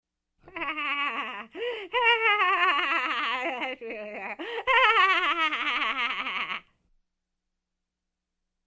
Звук смеха ведьмы
Противная колдовская персона дразнится
vedma-draznitsja.mp3